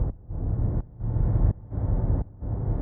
Index of /musicradar/sidechained-samples/170bpm